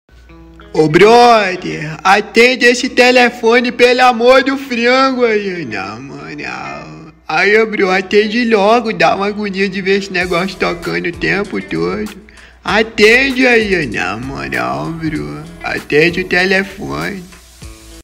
Categoria: Toques